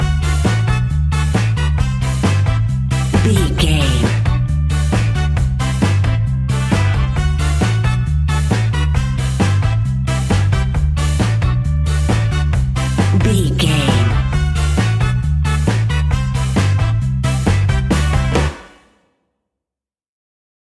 Classic reggae music with that skank bounce reggae feeling.
Aeolian/Minor
reggae instrumentals
laid back
chilled
off beat
drums
skank guitar
hammond organ
percussion
horns